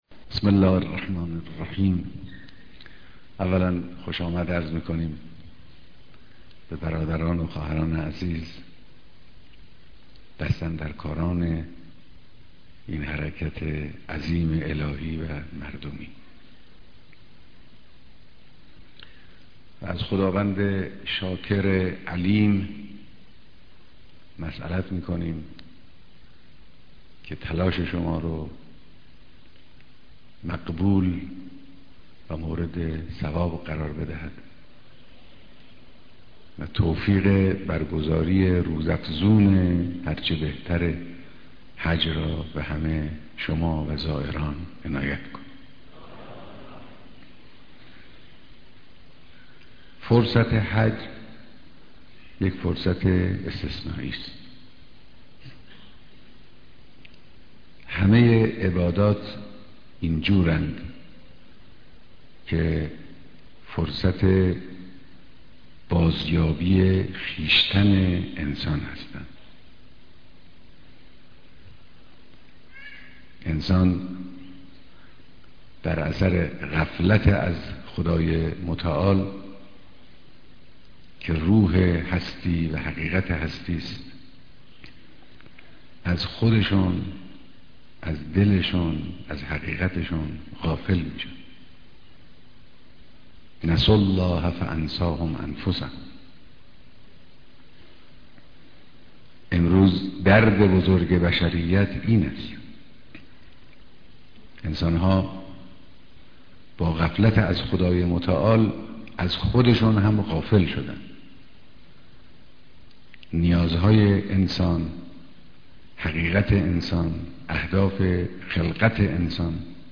بيانات در ديدار دست اندركاران مراسم حج